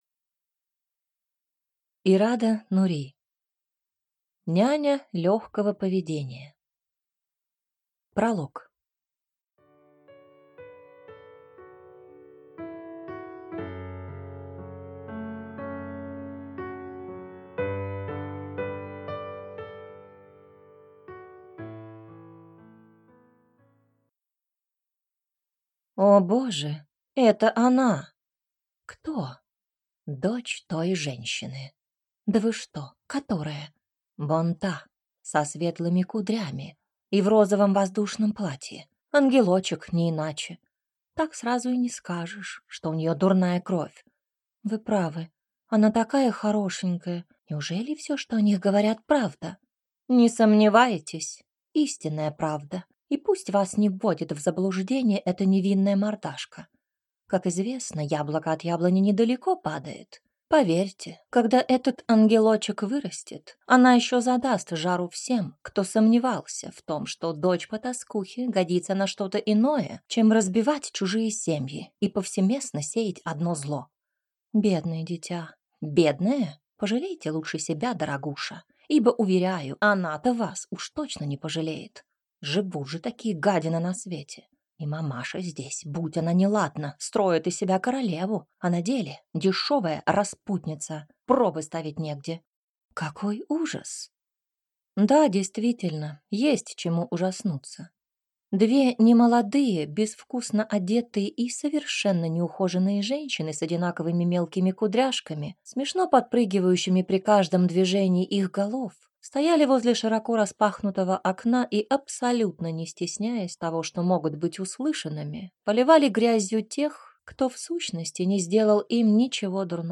Аудиокнига Няня лёгкого поведения | Библиотека аудиокниг